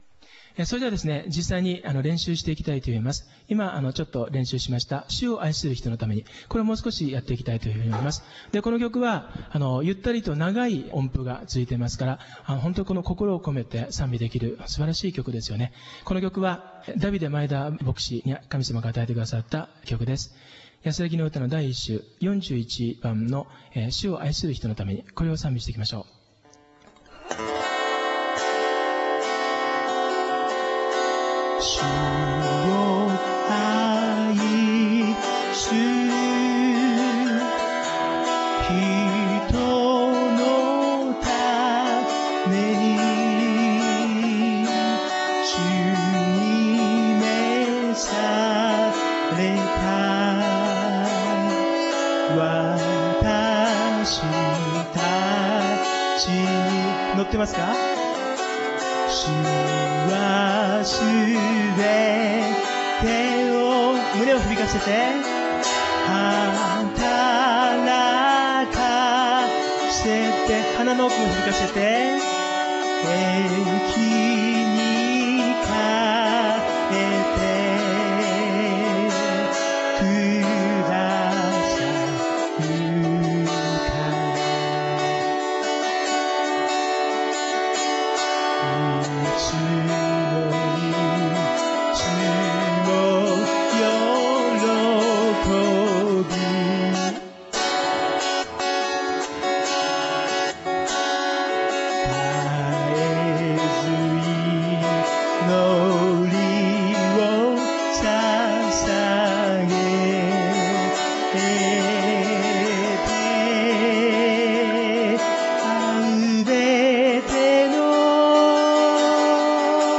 −ゴスペル・ボーカル教室−
ゆったりとした長い音符が続いていて、心をこめて賛美できる曲
グルーヴにのって、胸と頭を響かせながら
高い音も低い音も胸を響かせて
高い音は頭声（ヘッドボイス）になりやすいが、胸を響かせると倍音が広がって、ゆたかな音になる